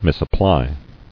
[mis·ap·ply]